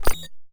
Misc Popup Glitch 003.wav